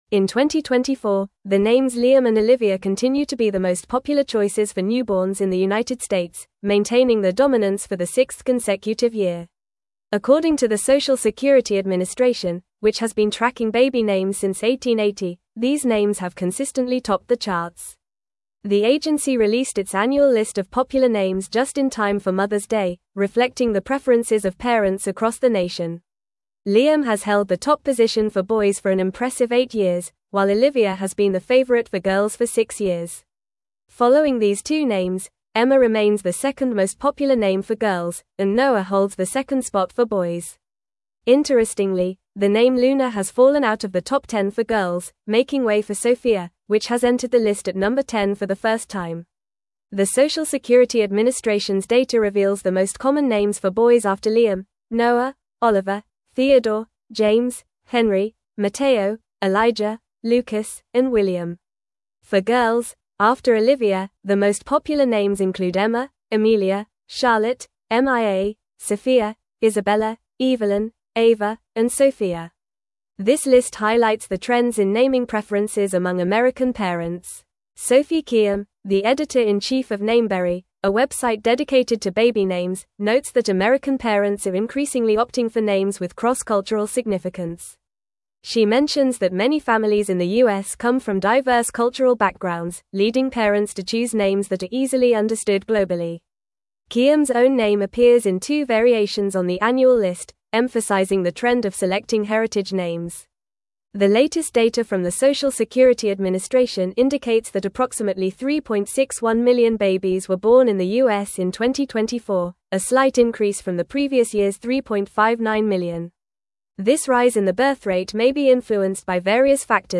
Fast
English-Newsroom-Advanced-FAST-Reading-Liam-and-Olivia-Remain-Top-Baby-Names-for-2024.mp3